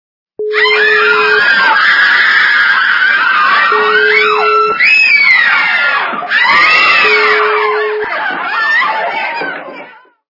Визги - в женской раздевалке Звук Звуки Крики - в жіночій роздівалці
» Звуки » Смешные » Визги - в женской раздевалке
При прослушивании Визги - в женской раздевалке качество понижено и присутствуют гудки.